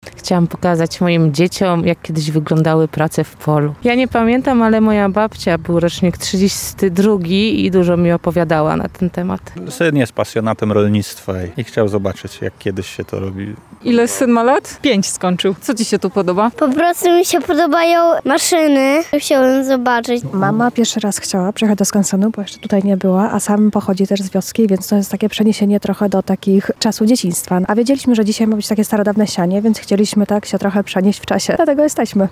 Pług, radło, wał czy siewnik – to tylko niektóre z urządzeń dawniej używanych na wsi, które dziś można było zobaczyć w akcji. Okazją do tego był pokaz wiosennych prac polowych w lubelskim skansenie.
Dlatego jesteśmy – mówią zwiedzający.